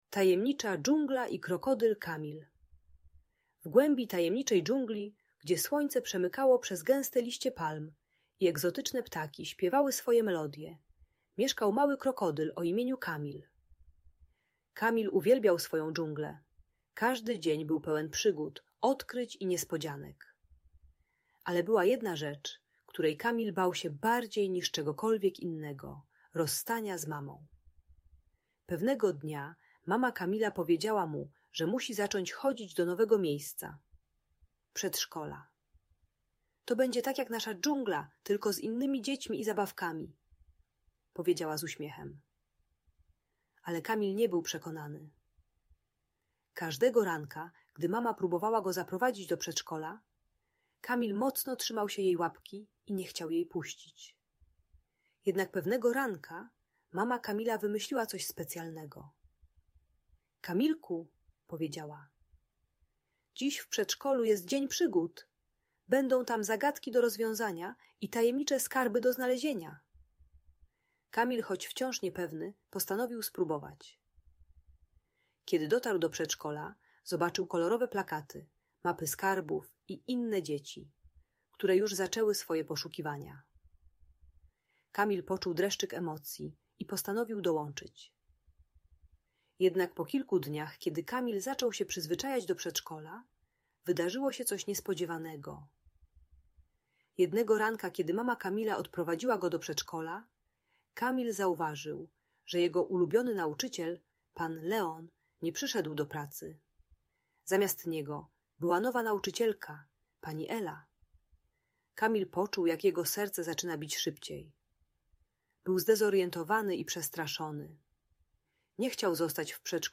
Tajemnicza dżungla - Przywiązanie do matki | Audiobajka